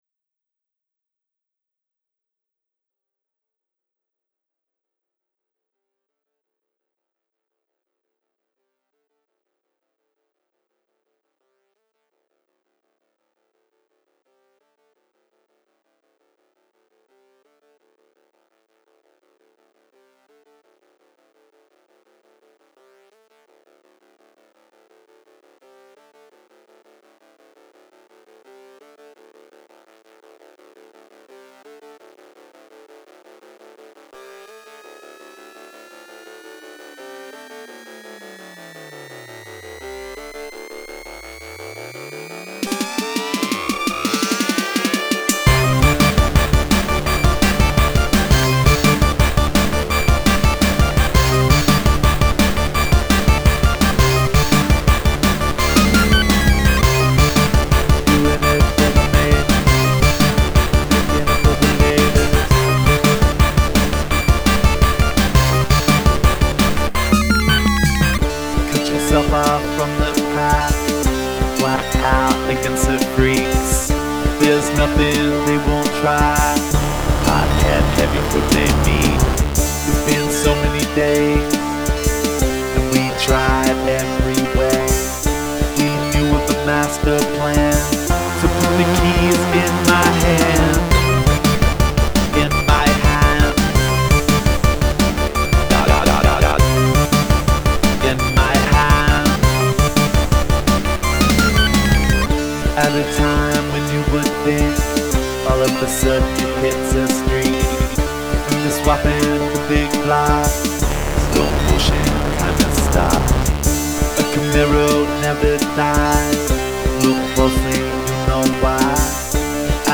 Punk & Stoner Rock Covers on C64